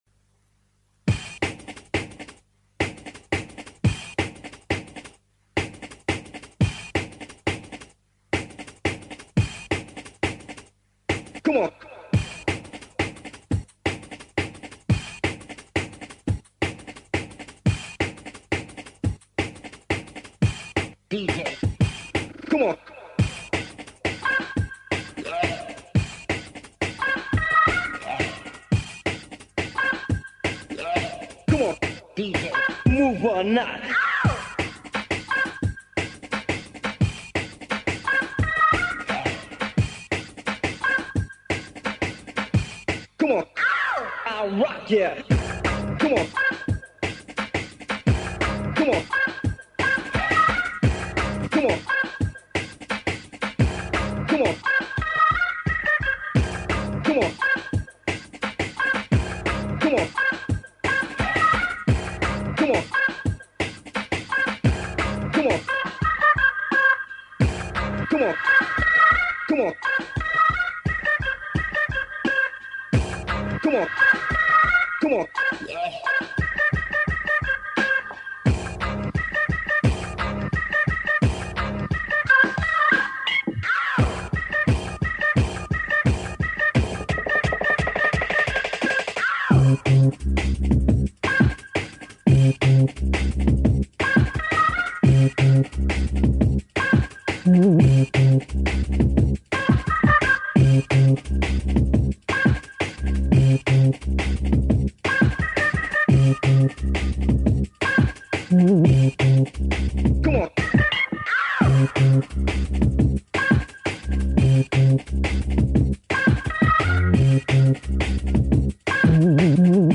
оцифровки скассет.